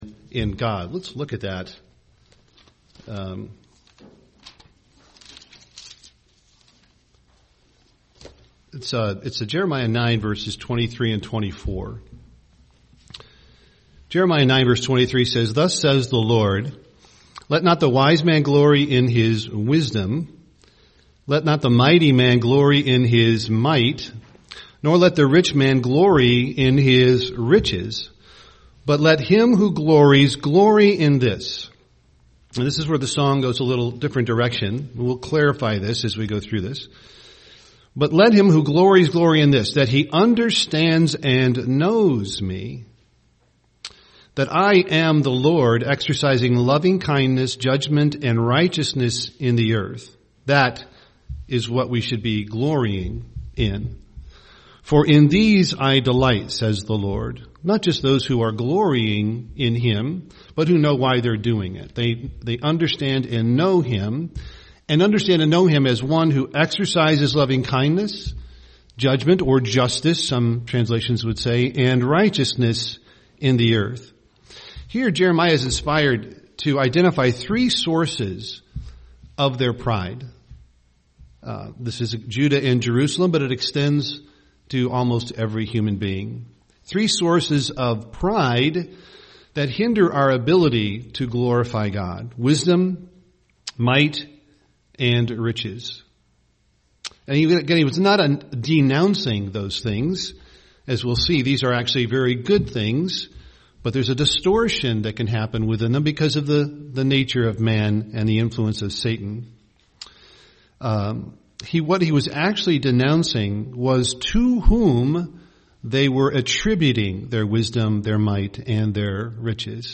UCG Sermon God's glory Studying the bible?